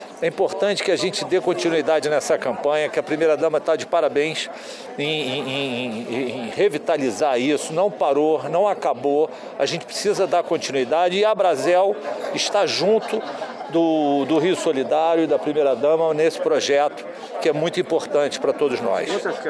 O evento foi realizado no Restaurante Aprazível, em Santa Teresa, na região central da cidade.